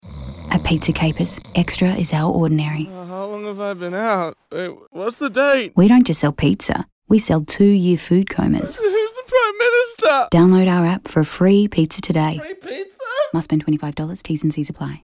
To bring this idea to life, we delivered radio with an extra attention-grabbing tone that over-emphasised our generous servings and flavours.